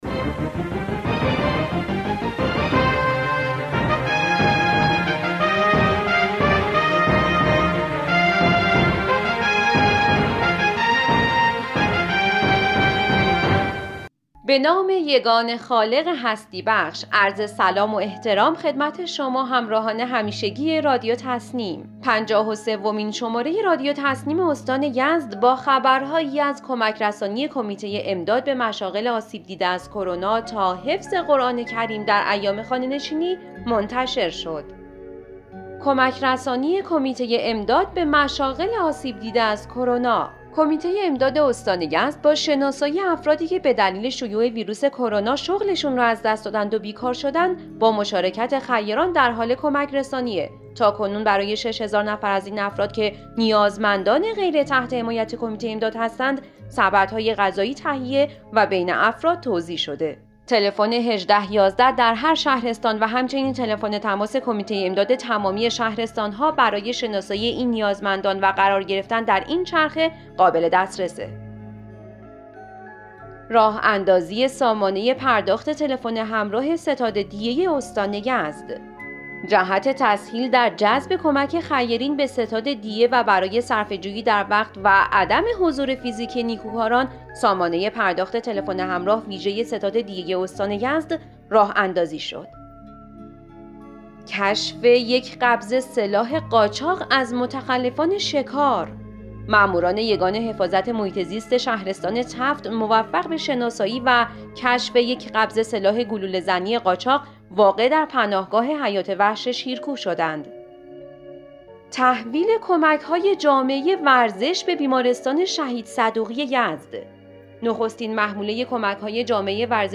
به گزارش خبرگزاری تسنیم از یزد, پنجاه و سومین بسته خبری رادیو تسنیم استان یزد با خبرهایی از کمک‌رسانی کمیته امداد به مشاغل آسیب دیده از کرونا, راه‌اندازی سامانه پرداخت تلفن همراه ستاد دیه استان, کشف یک قبضه سلاح قاچاق از متخلفان شکار, تحویل کمک‌های جامعه ورزش به بیمارستان شهید صدوقی, اعلام بلامانع فعالیت مطب برخی پزشکان و حفظ قرآن کریم در ایام خانه‌نشینی منتشر شد.